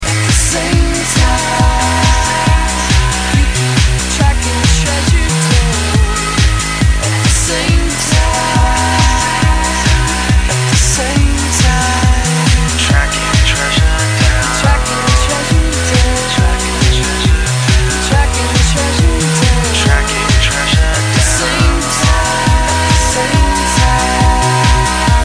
Trance